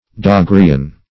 Search Result for " daguerreian" : The Collaborative International Dictionary of English v.0.48: Daguerrean \Da*guer"re*an\ (d[.a]*g[e^]r"[i^]*an), Daguerreian \Da*guerre"i*an\, a. Pertaining to Daguerre, or to his invention of the daguerreotype.